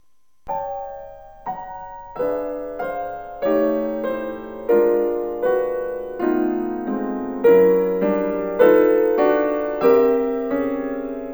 piano.